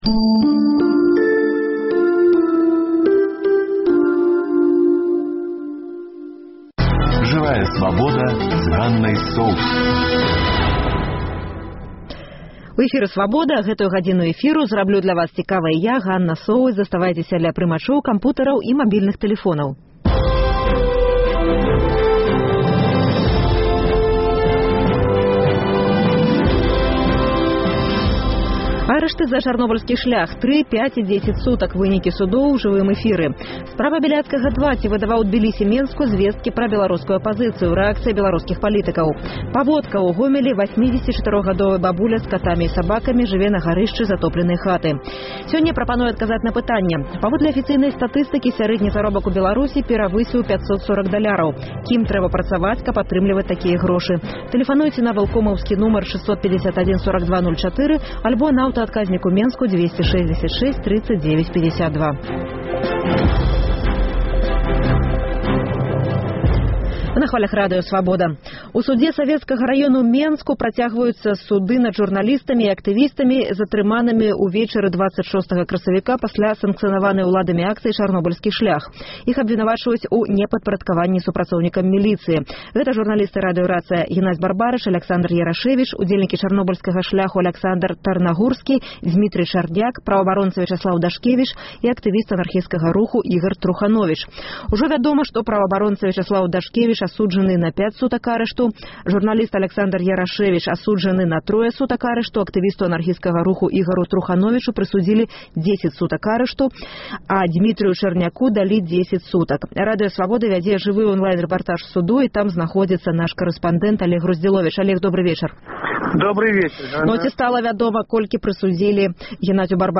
Сёньня ў праграме: Суды над журналістамі і актывістамі за Чарнобыльскі шлях. Жывыя ўключэньні з Савецкага суду.